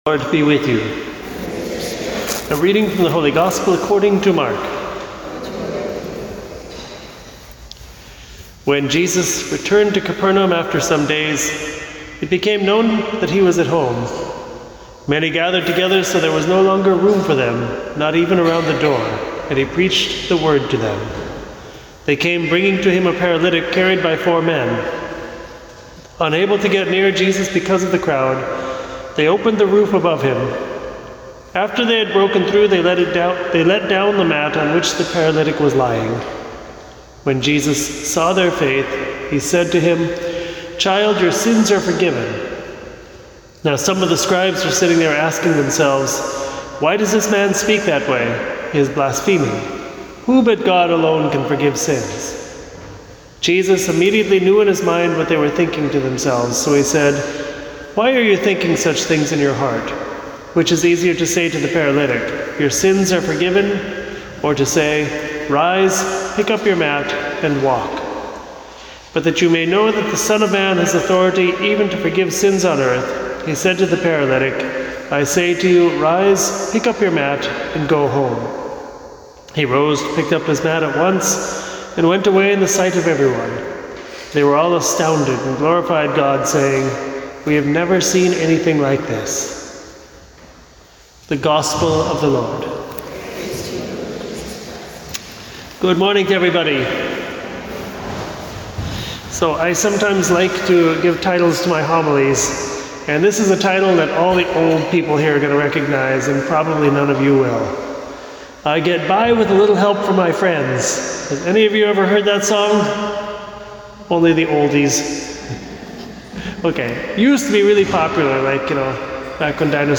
Family Friday Homily